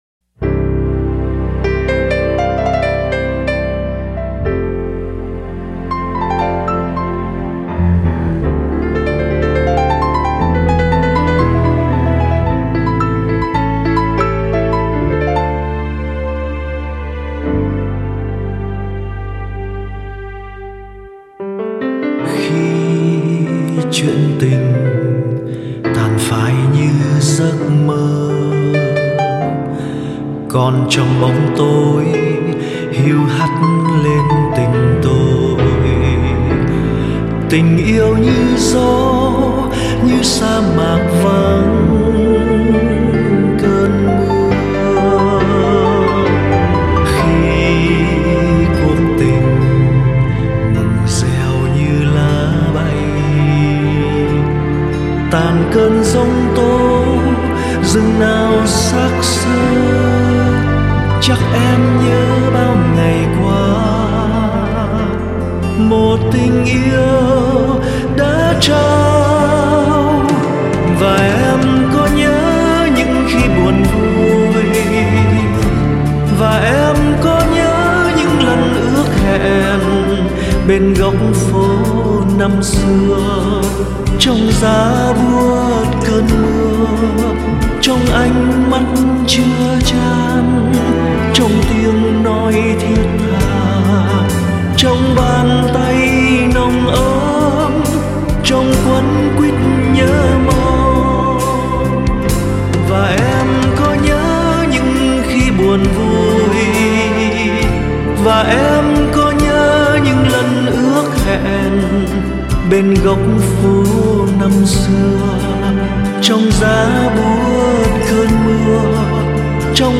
Tác giả trình bày